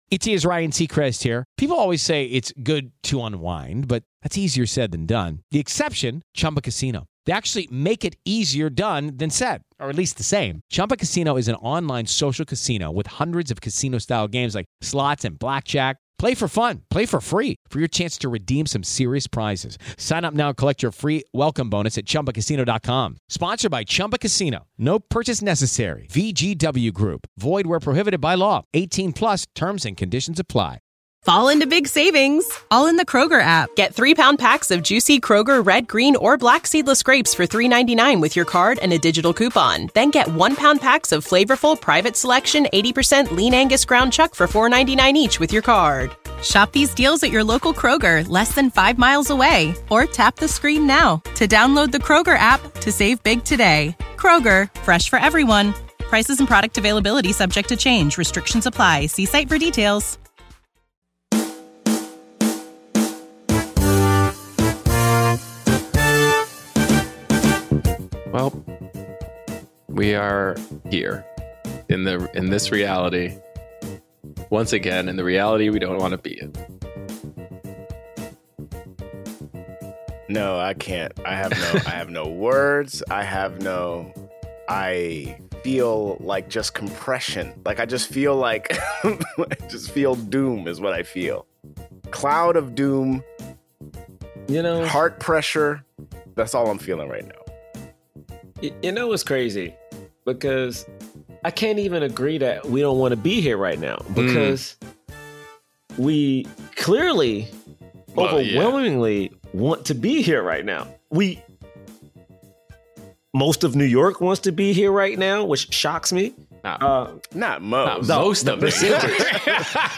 Black Men Can't Jump [In Hollywood] is a comedic podcast that reviews films with leading actors of color and analyzes them in the context of race and Hollywood's diversity issues.